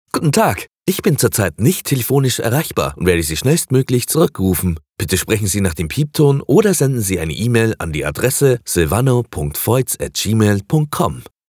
Deep, Cool, Commercial, Natural, Warm
Telephony